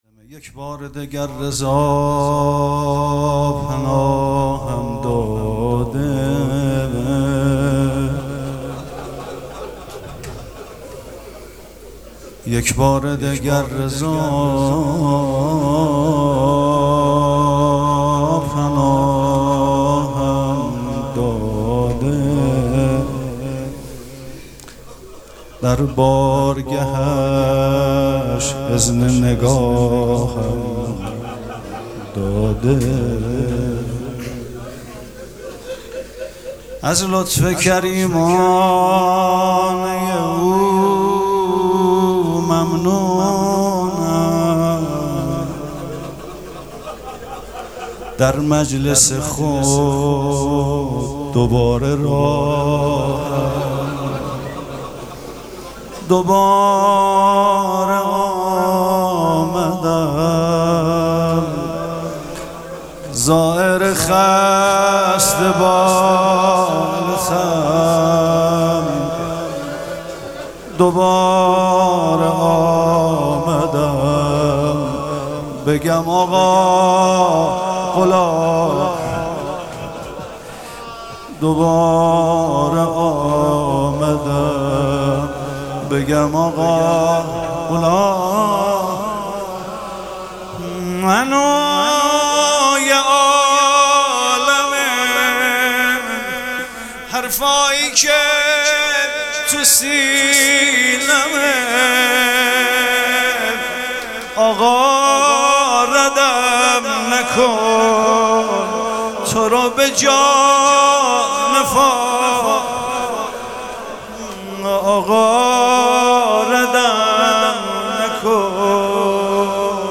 مراسم عزاداری شب چهارم محرم الحرام ۱۴۴۷
شعر خوانی